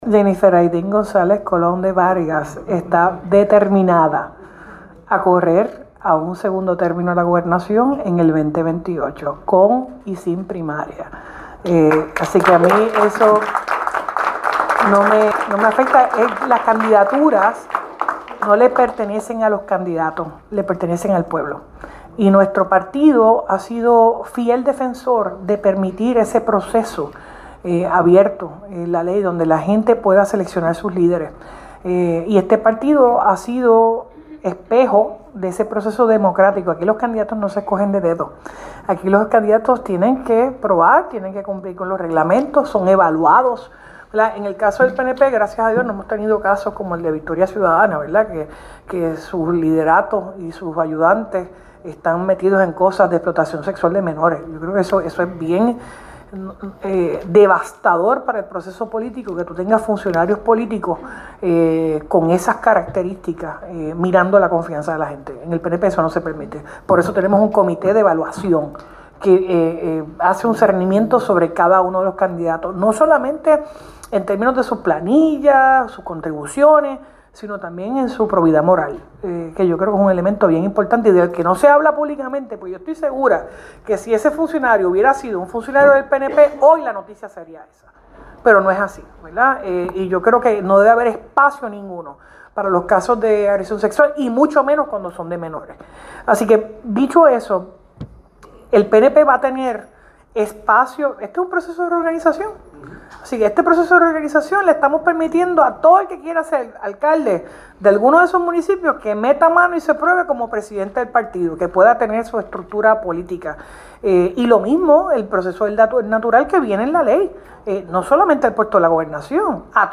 (17 de febrero de 2026) A preguntas de la prensa durante el directorio dirigido por la presidenta del PNP,  y gobernadora de Puerto Rico, Jenniffer  González Colón,  dijo  está determinada a correr a un segundo término  de la gobernación en el 2028 con y sin primaria.